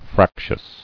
[frac·tious]